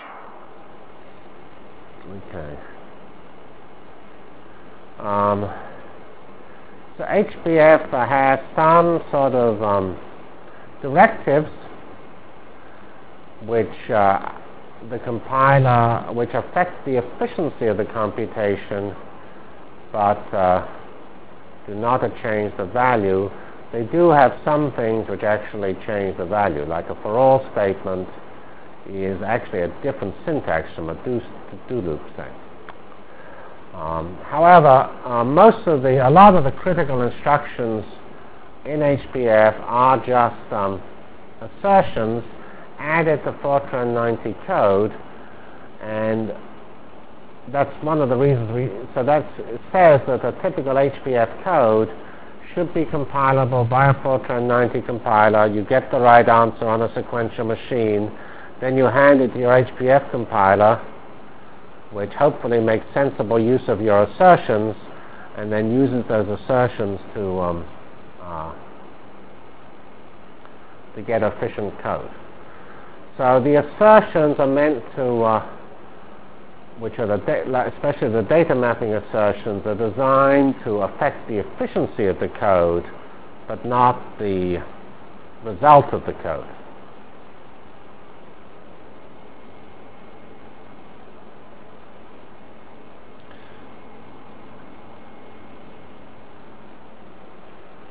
From CPS615-Introduction to Virtual Programming Lab -- Problem Architecture Continued and Start of Real HPF Delivered Lectures of CPS615 Basic Simulation Track for Computational Science -- 26 September 96. *